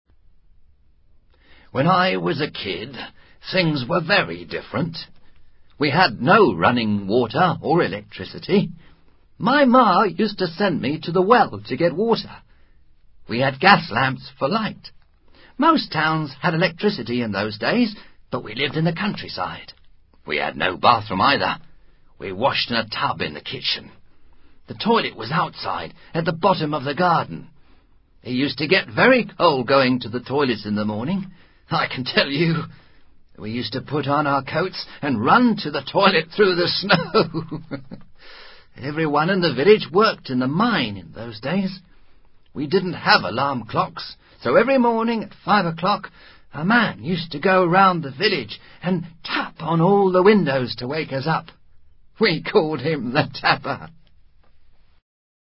Un anciano habla sobre cómo era la vida en el pasado.